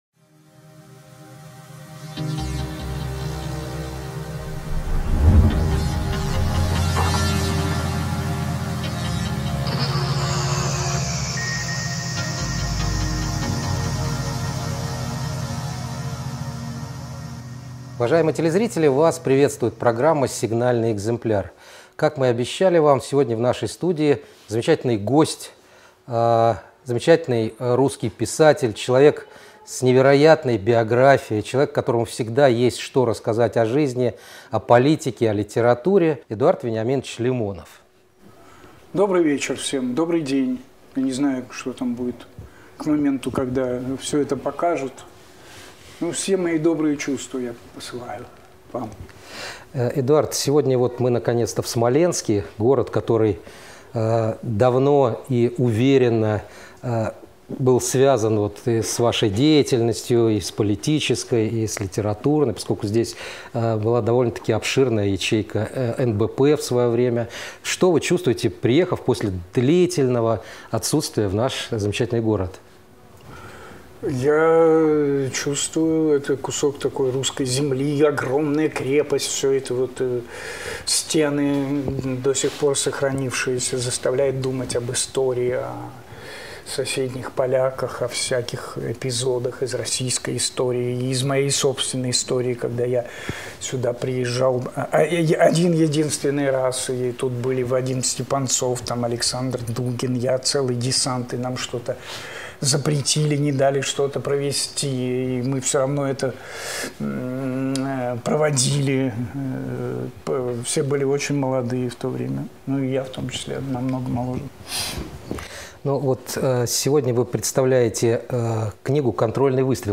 Интервью с Эдуардом Лимоновым от 24.07.2018 без цензуры и без купюр. О жизни, литературе, искусстве, политике и вообще обо всем.